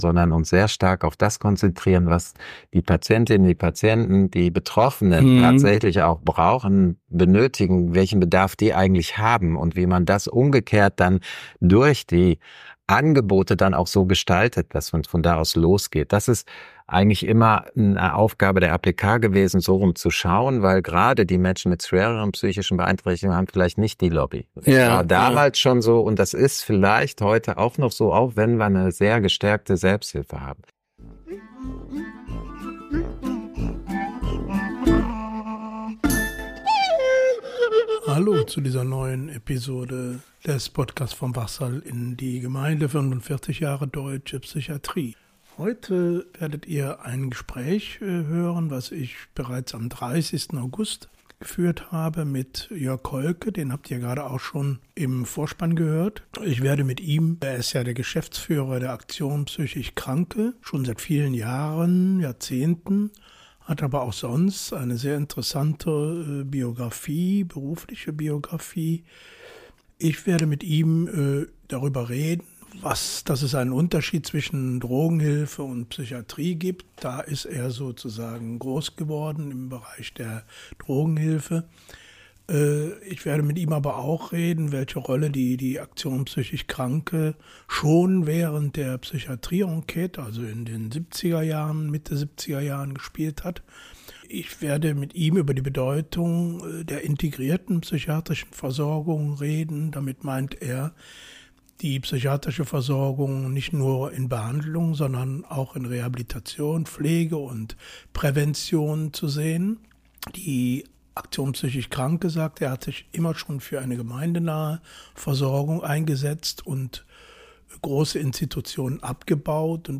Musik dazu von Beiden.